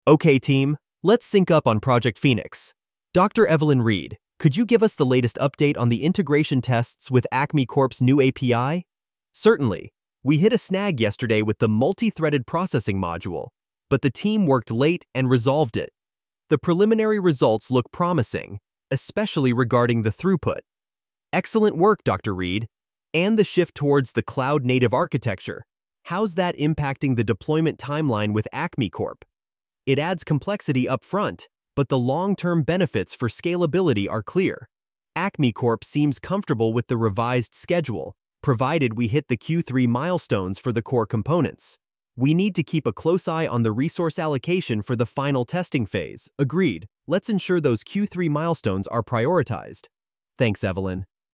meeting_snippet.mp3